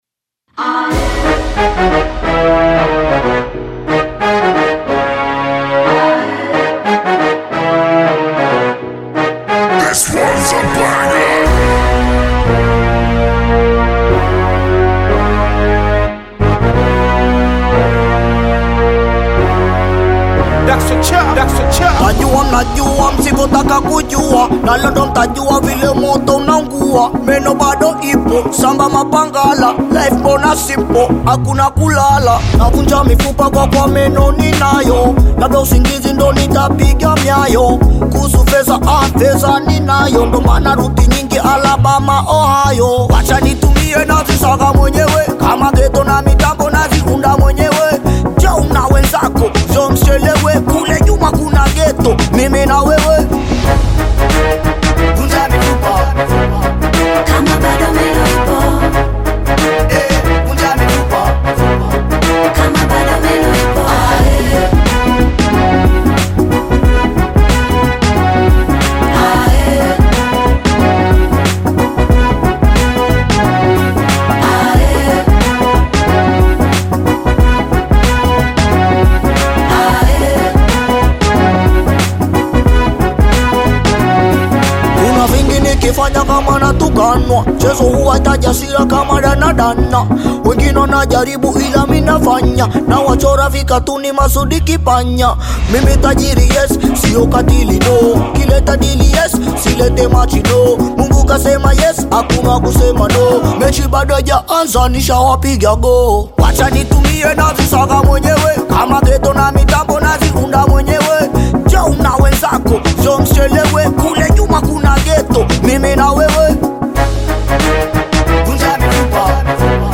Tanzanian Bongo Flava artist, singer, and songwriter
Bongo Flava You may also like